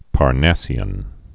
(pär-năsē-ən)